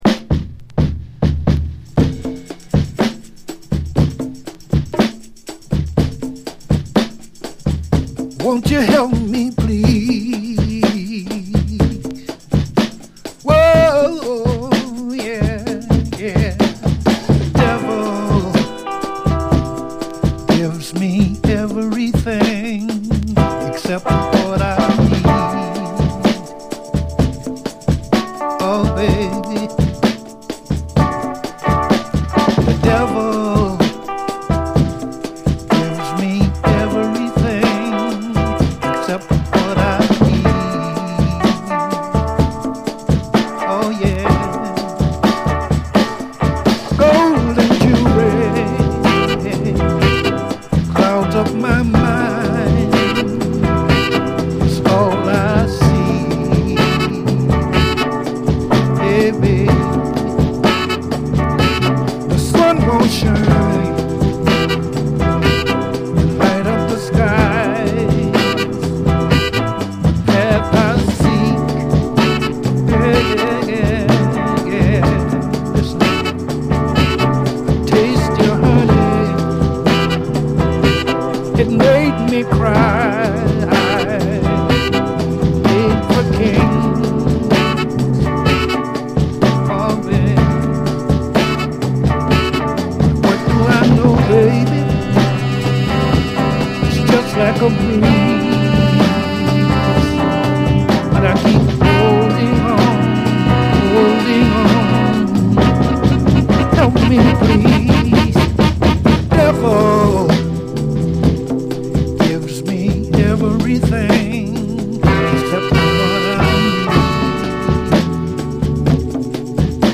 SOUL, 70's～ SOUL, 7INCH
ドラム・ブレイクから哀愁がほとばしる、やるせなさ満点、男泣きの傑作メロウ・ソウル！